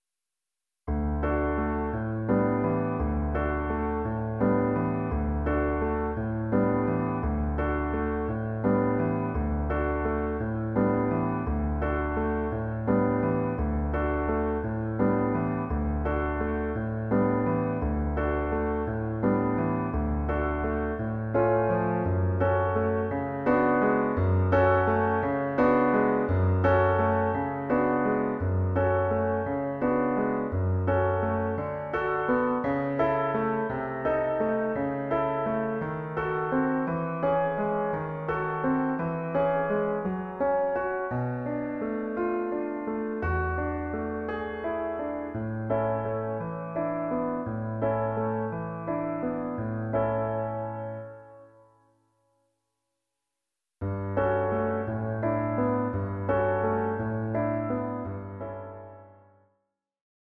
Écouter l'audio de l'accompagnement (environ 1 minute)